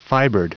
Prononciation du mot fibered en anglais (fichier audio)
Prononciation du mot : fibered